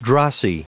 Prononciation du mot drossy en anglais (fichier audio)
drossy.wav